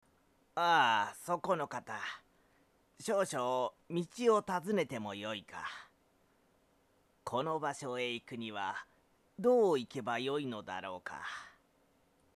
---------------< Sample Voice >---------------
マイク：ＳＯＮＹ　ＥＣＭ−ＭＳ９０７
仙人とは違った、落ち着いた感じの老人を演じてみました。